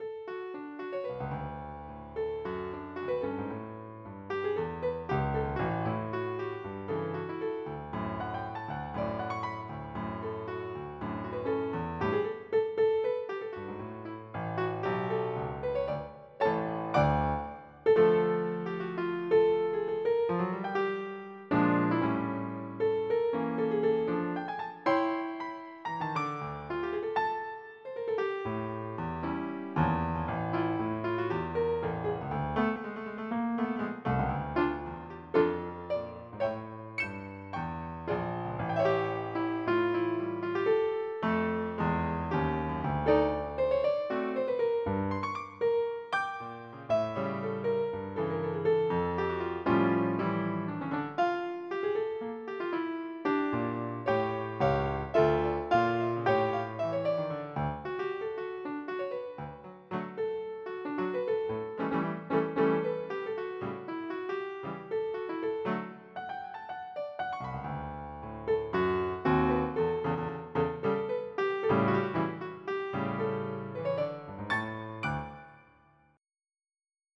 / tango.iso / dat / s3m0 / amedialu.mid ( .mp3 ) < prev next > MIDI Music File | 1995-09-30 | 3KB | 2 channels | 44,100 sample rate | 1 minute, 17 seconds Type General MIDI